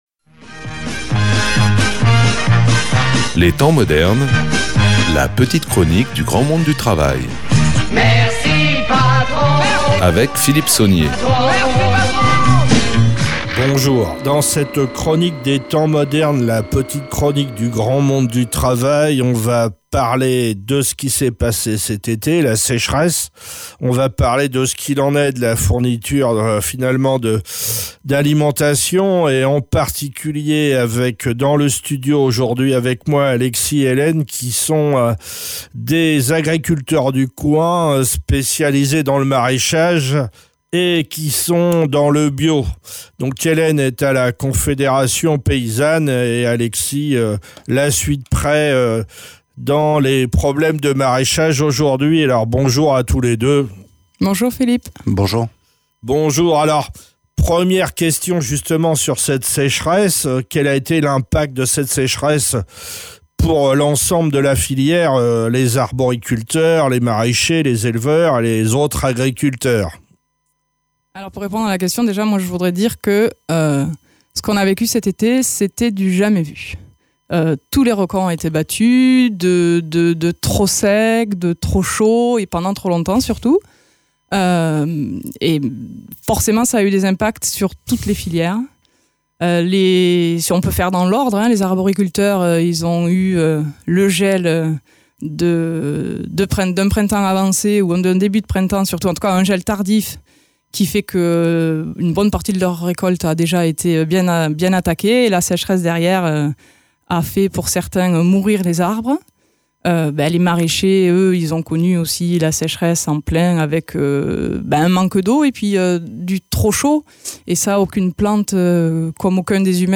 La sécheresse et la guerre en Ukraine bousculent notre consommation alimentaire. Avec des paysans de la région, investis dans le bio, trions ce qu’il en est de la réalité et de ce qui est du domaine de la spéculation.